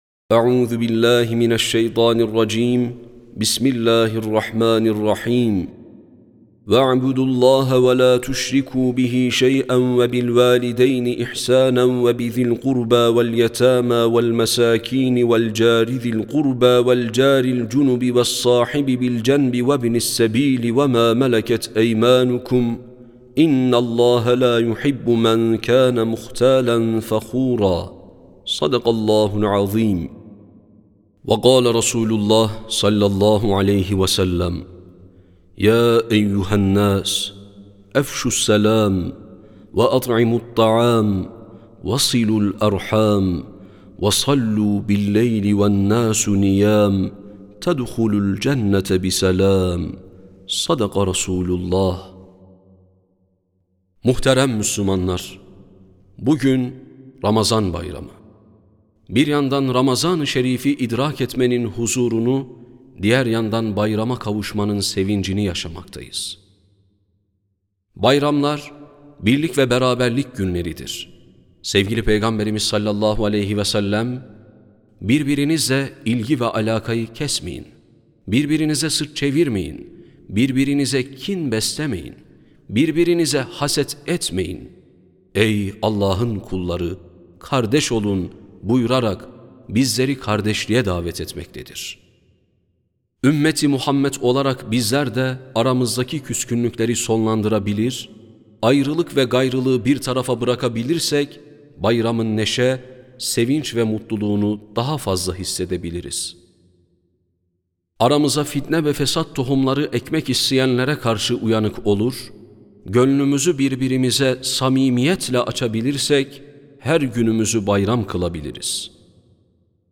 Sesli Hutbe (Ramazan Bayramı).mp3